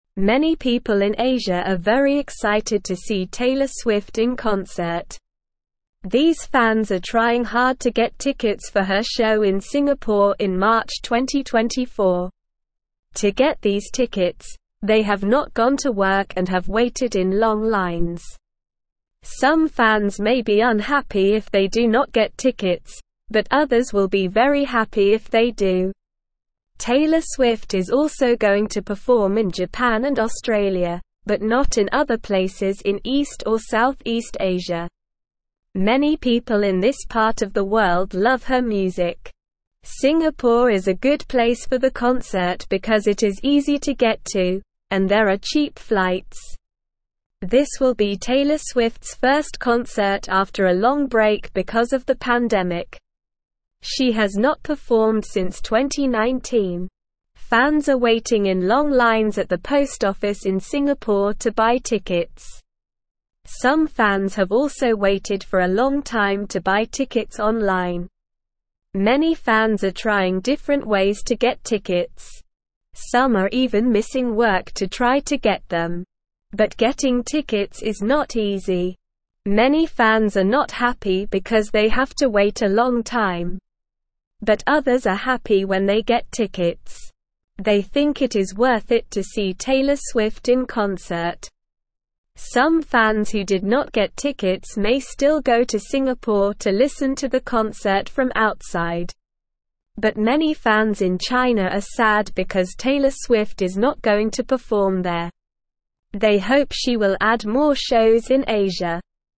Slow
English-Newsroom-Beginner-SLOW-Reading-Fans-Work-Hard-for-Taylor-Swift-Tickets.mp3